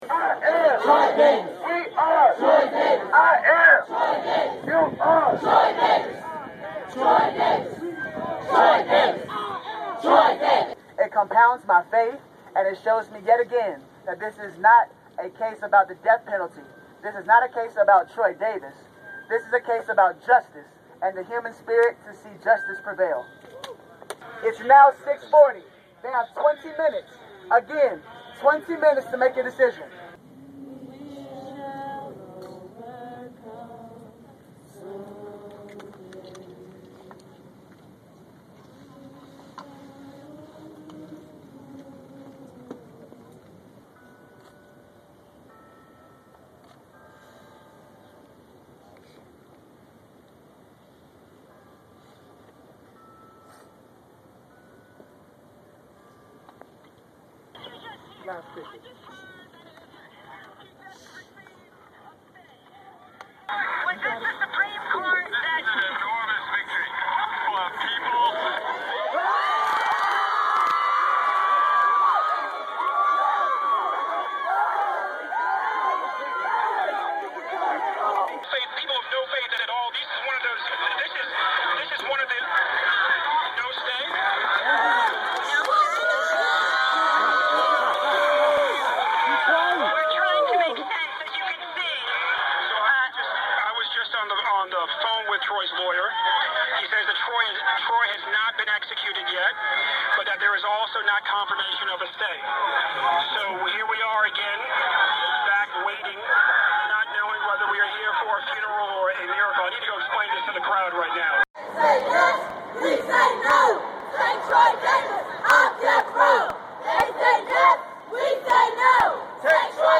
Somber, emotional, and peaceful, the demonstrators passed the time singing spirituals, reading personal messages from Troy Davis, and eventually engaging in silent prayer.
At 7 p.m., nearby church bells broke the silence, indicating that the scheduled time of execution had arrived. A few tense minutes passed without any news, when someone put a radio up to a megaphone.
Disappointed and confused, the crowd began a spontaneous march to the Supreme Court, chanting "They Say Death Row! We say 'Hell, No!"